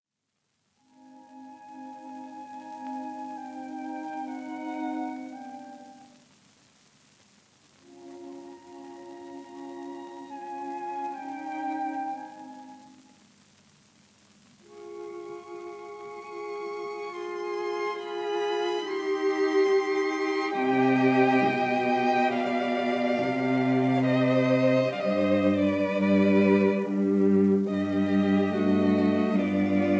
violins
viola
cello
in C minor, ending in C major — Grave e cantabile